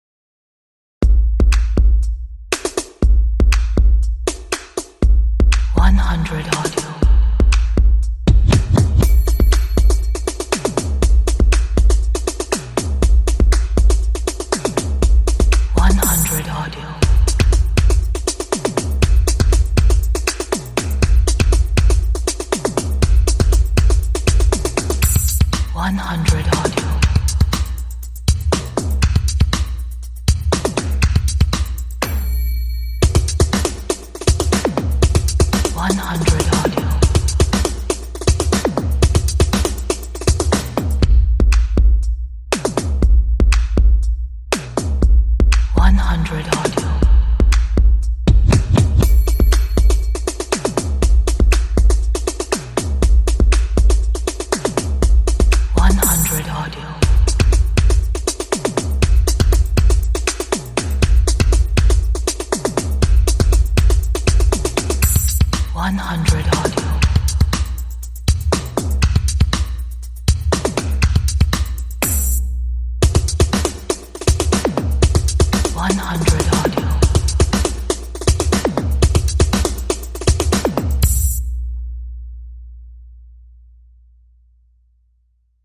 一首充满能量和节奏的音乐，由拍手声，镲和鼓组成。